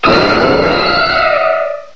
aerodactyl_mega.aif